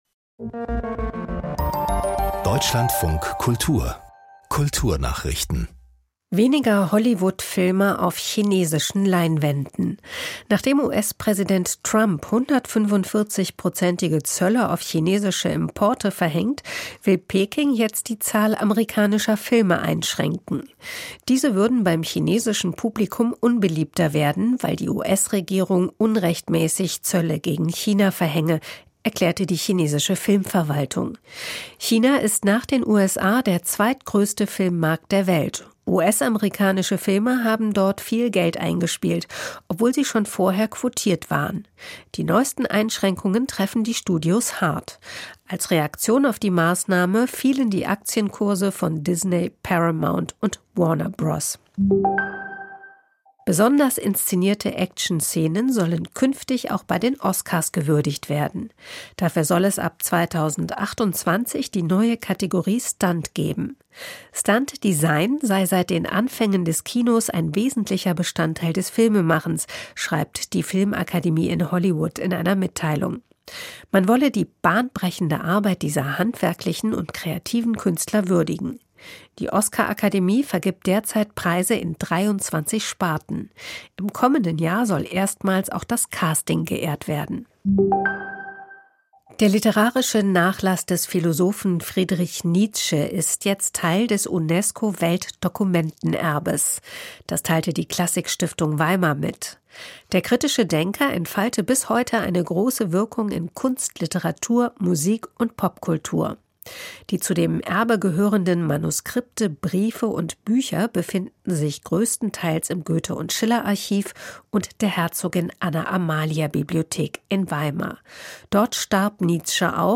Kommentar - Nach vertagter Richterwahl braucht Koalition auch mediale Resilienz - 14.07.2025